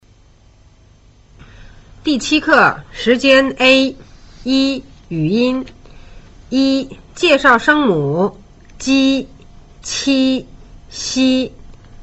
這組聲母是舌面音, 發音時牙齒合上, 舌面緊張, 抬高靠近前硬顎。